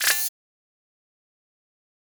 combobreak.ogg